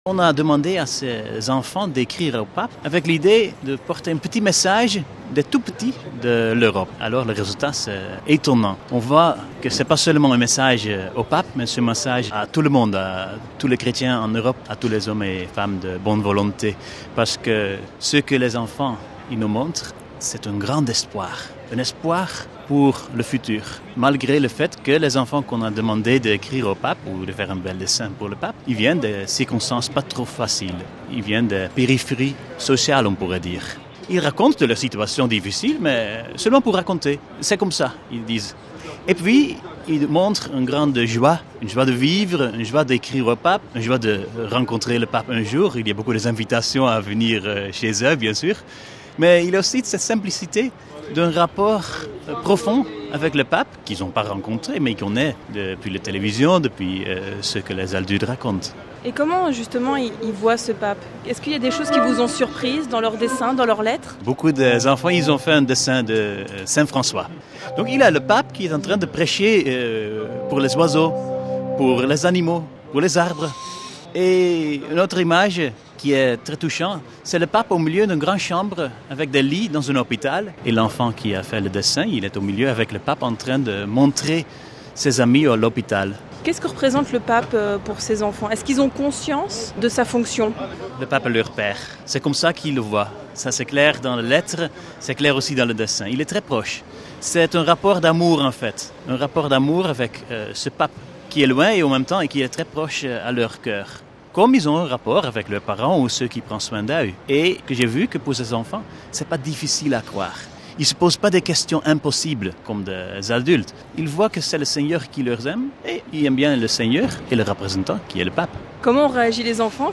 Des propos recueillis